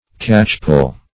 Catchpoll \Catch"poll`\, n.